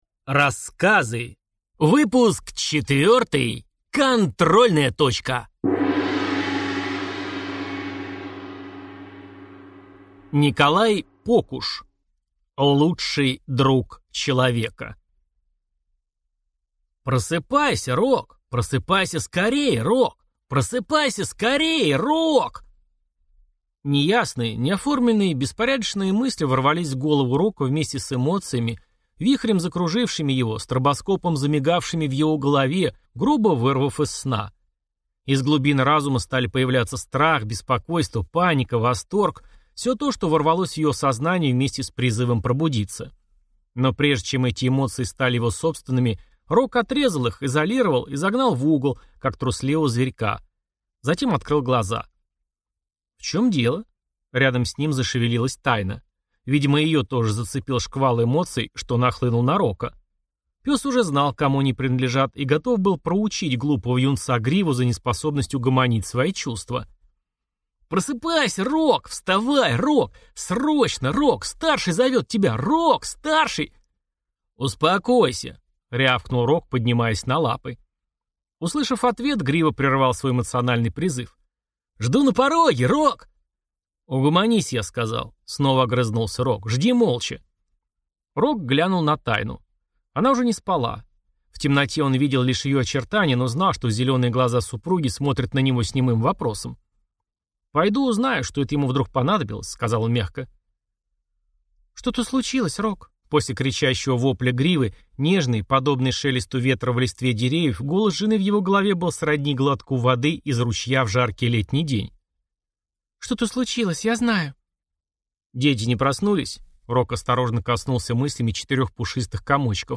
Аудиокнига Рассказы 4 | Библиотека аудиокниг